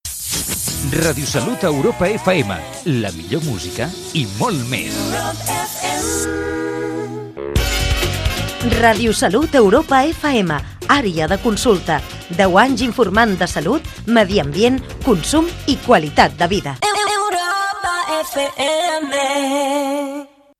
Indicatiu de l'emissora i identificació de Àrea de consulta
FM